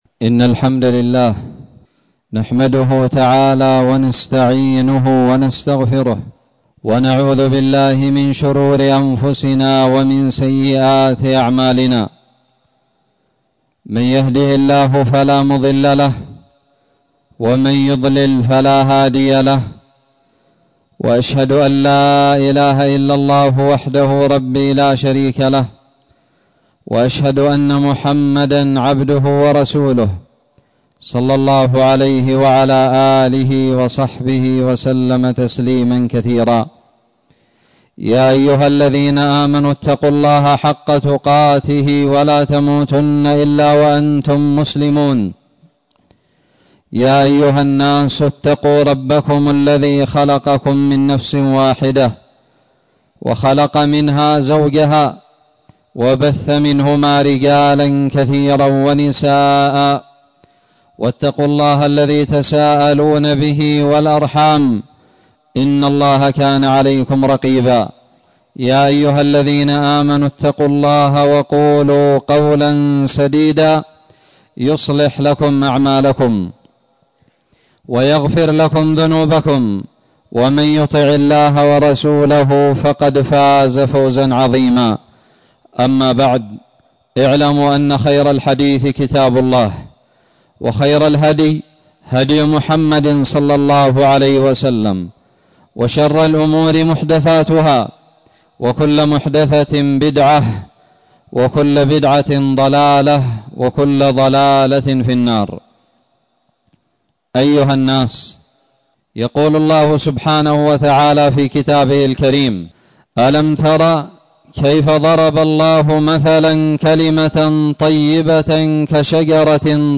خطب الجمعة
ألقيت بدار الحديث السلفية للعلوم الشرعية بالضالع في 14 محرم 1441هــ